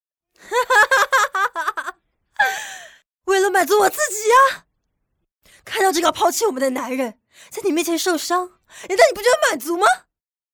影视语音
雅（中年）：约40岁，被逼疯的富家千金。音色嘶哑，语速过快，表现出精神不稳定的状态。她的声音中充满了混乱和不安，仿佛随时会崩溃，生动刻画了一个曾经风光无限、如今却被生活逼至绝境的悲惨人物形象。